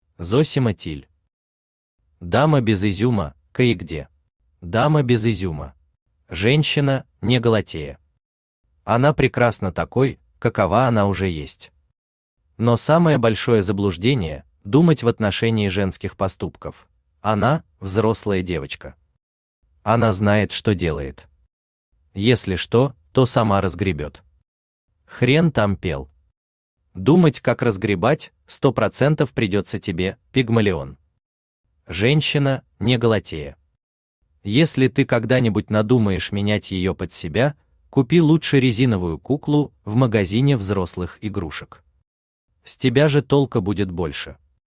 Аудиокнига Дама без изюма / Кое-где | Библиотека аудиокниг
Aудиокнига Дама без изюма / Кое-где Автор Зосима Тилль Читает аудиокнигу Авточтец ЛитРес.